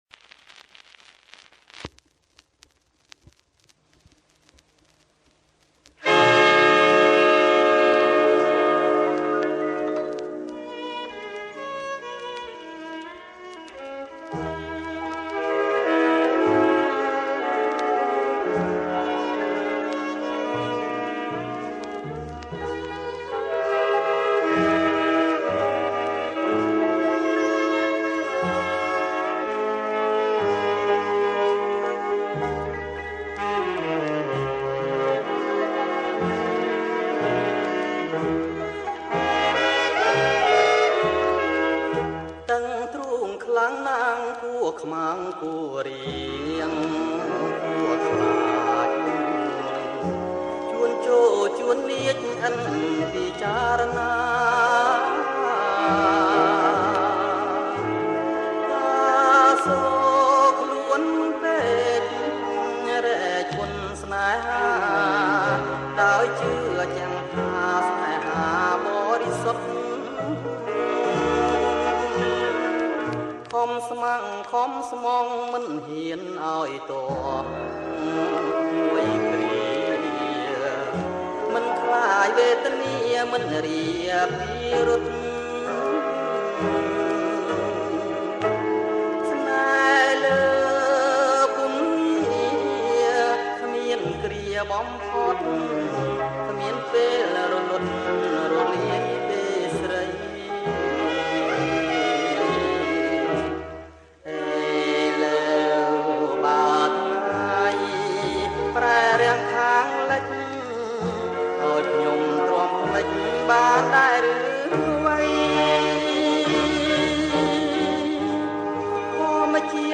• ប្រគំជាចង្វាក់ Blue
ប្រគំជាចង្វាក់ Blue